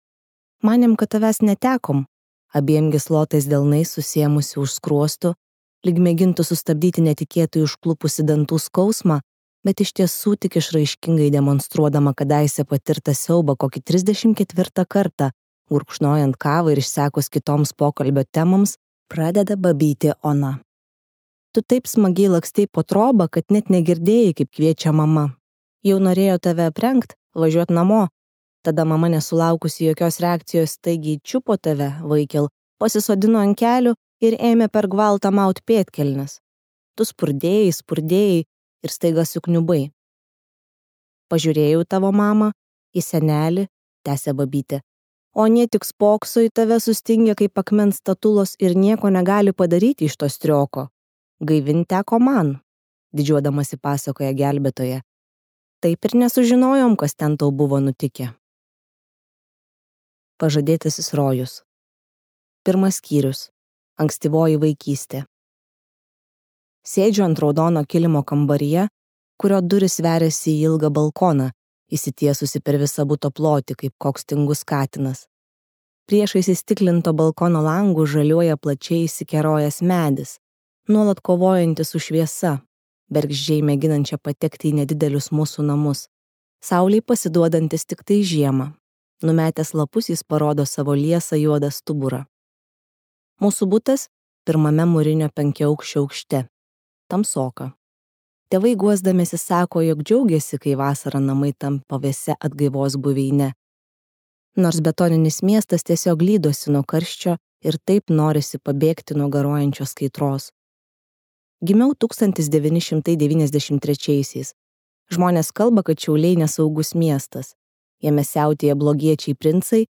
Pasaulietė | Audioknygos | baltos lankos